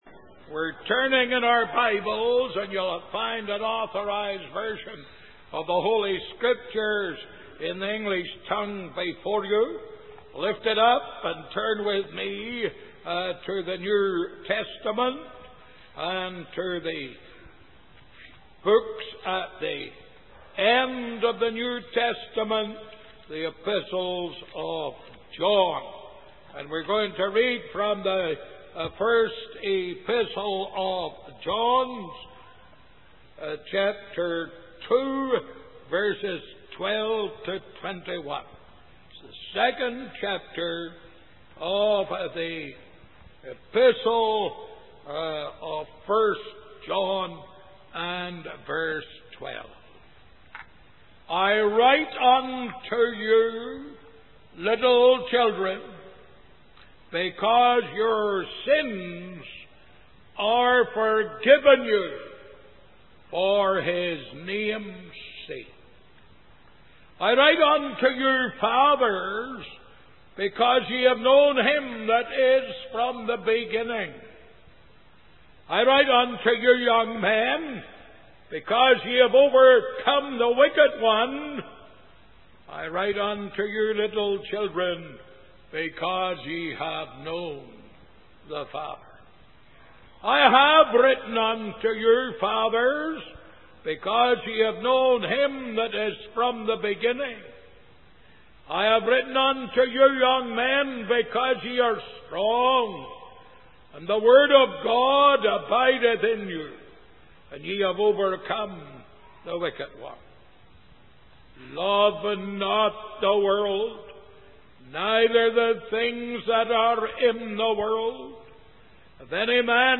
In this sermon, the preacher discusses the five great cardinalities of the Gospel: the book, the birth, the blight, the blood, and the basis.